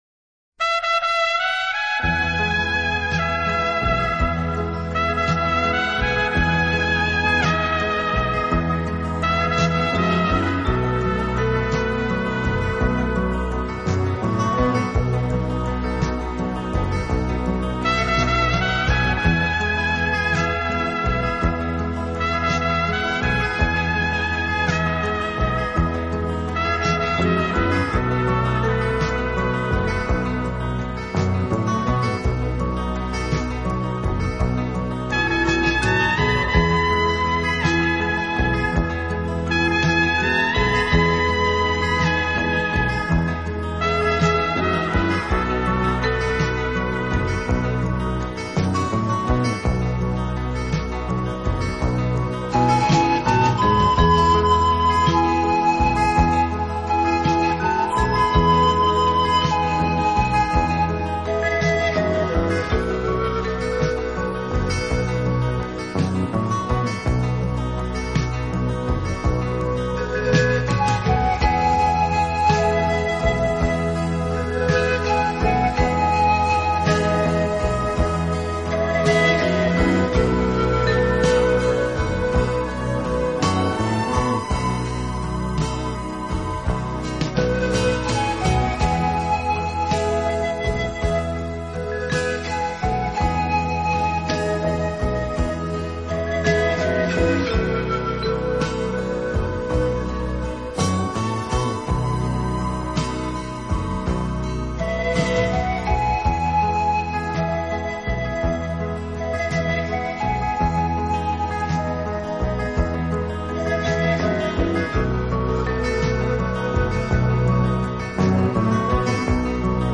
本套CD音乐之音源采用当今世界DVD音源制作最高标准：96Khz/24Bit取样录制，其音源所
小号：铜管乐器，号嘴杯形，号管细而长，弯成长圆形的圈，未端呈喇叭形，管上有三个
嘹亮的小号同样具有抒情的品质，它金属的身体无法拒绝它哽咽的气息。